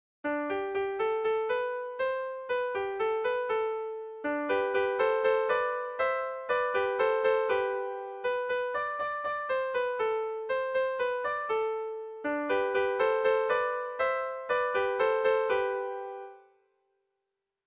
Sentimenduzkoa
Zortzikoa, txikiaren moldekoa, 4 puntuz (hg) / Lau puntukoa, txikiaren modekoa (ip)
A1A2BA2